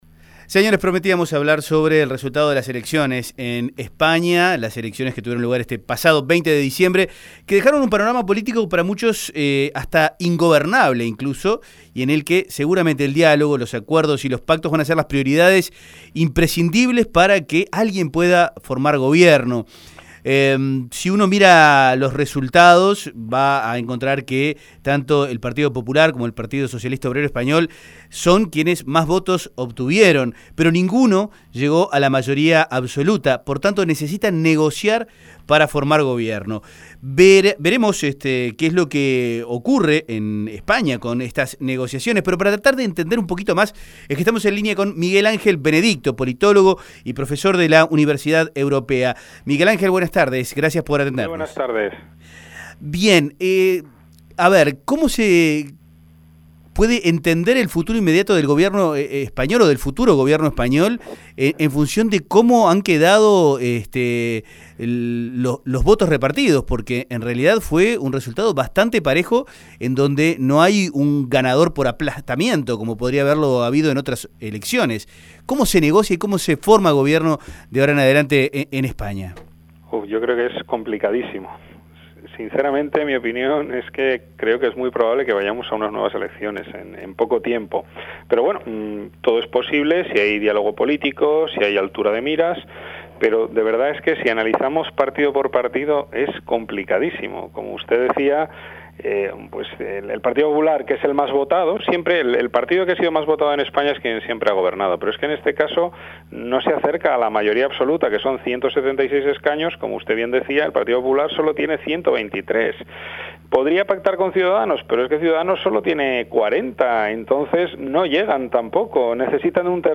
Descargar Audio no soportado El resultado de las elecciones del20-D ha dejado un panorama político para muchos ingobernable, dijo en diálogo con 810 Vivo.